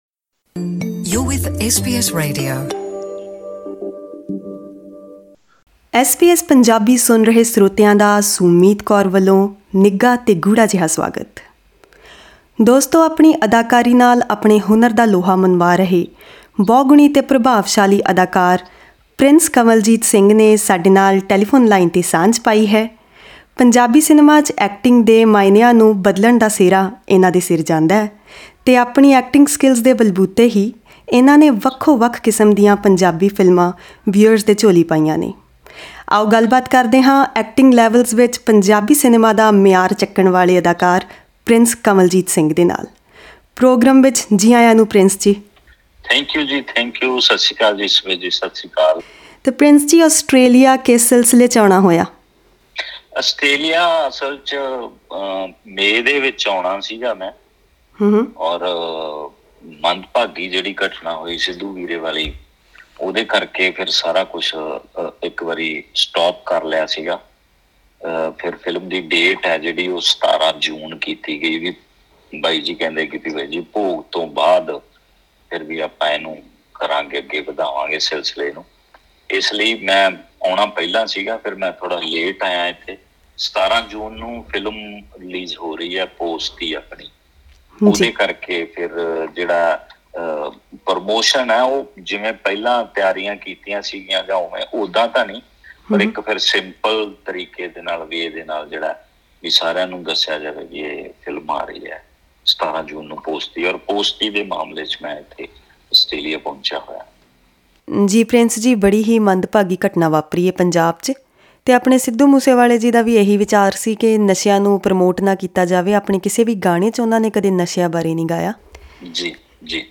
Ahead of the release of Punjabi film 'Posti', which addresses the drug menace, actor and writer Prince Kanwaljit Singh is on his Australia tour. In an exclusive interview with SBS Punjabi, the actor reveals details about his struggling days and how theatre and literature shaped him into becoming one of the finest actors in Punjabi cinema.